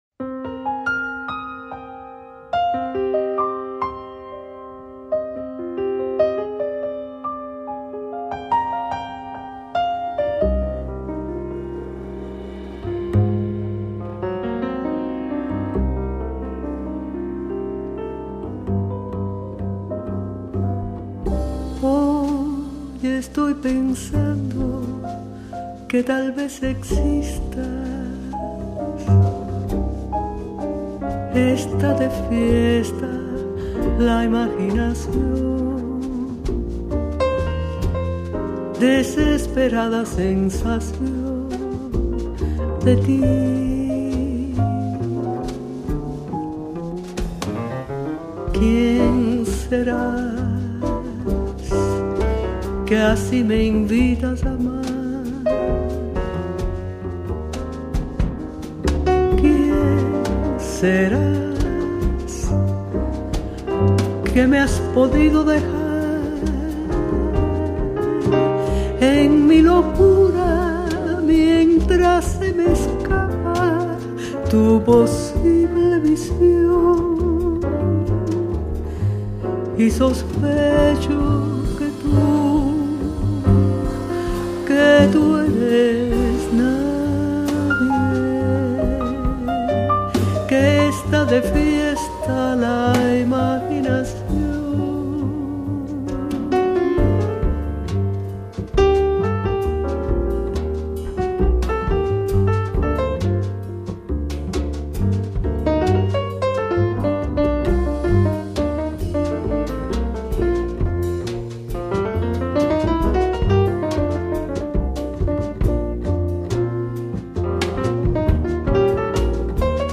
类型：爵士
浪漫优雅的南欧情歌
是钢琴、低音提琴和鼓的三重奏组合